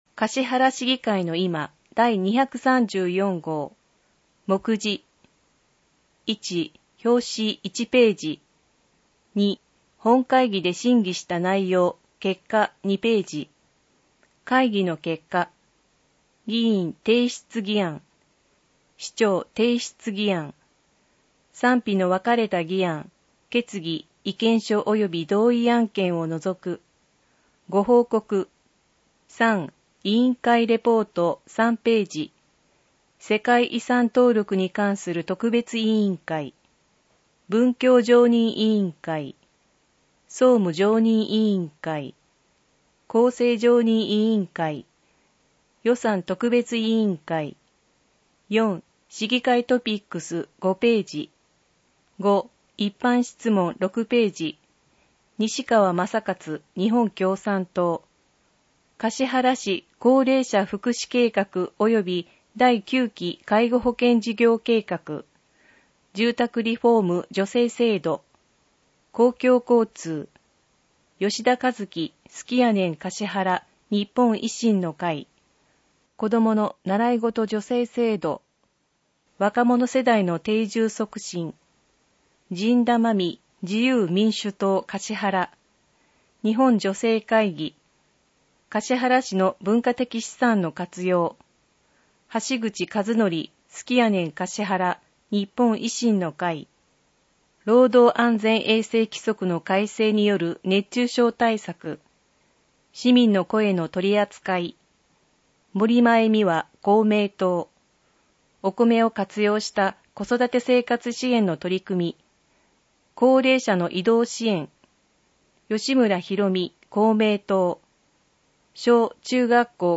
音訳データ
音訳データは、音訳グループ「声のしおり」の皆さんが音訳されたものを使用しています。